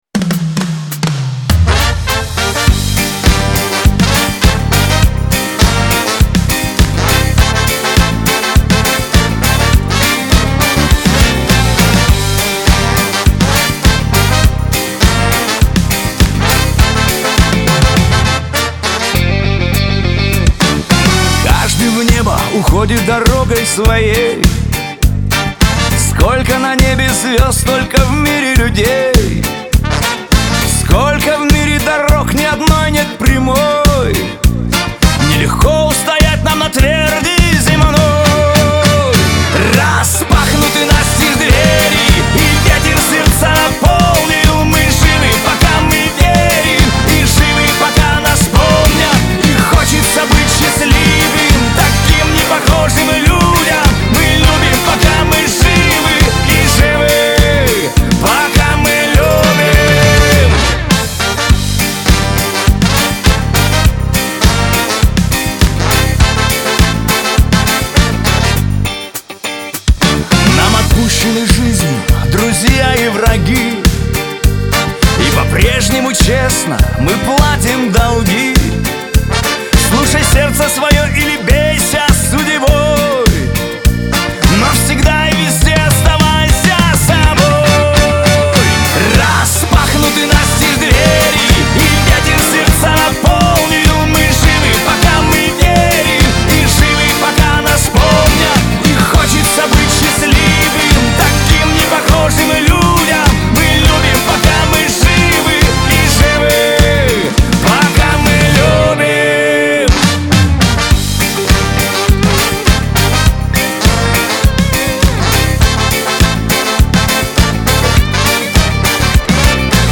pop
эстрада